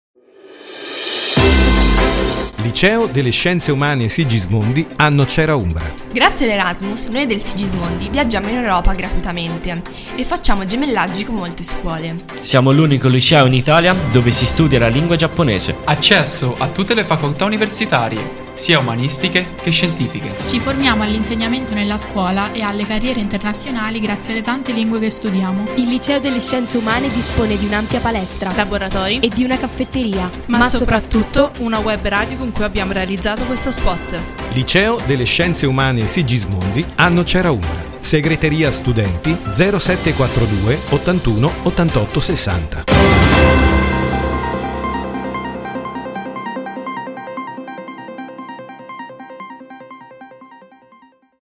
Spot-Liceo-2022.mp3